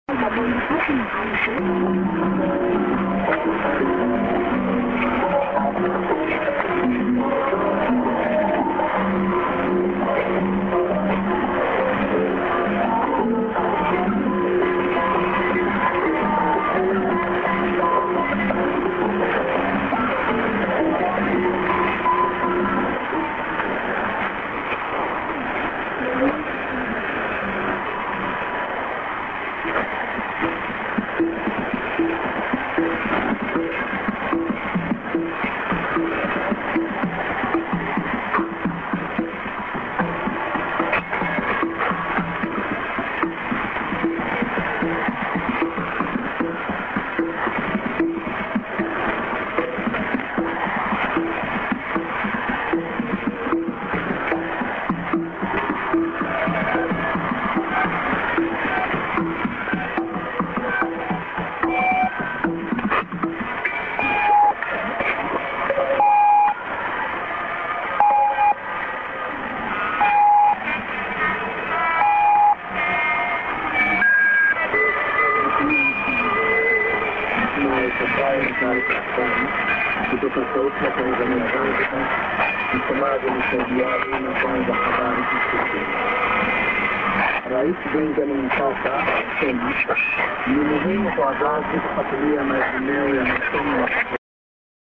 ->->00'30":durm->01'05":TS->->ID:…Tanzania Zanziba…(man)